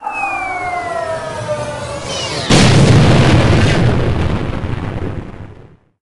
meteor_01.ogg